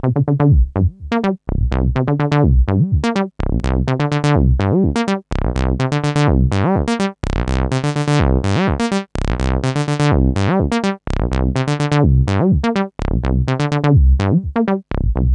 cch_acid_daft_125_Gb.wav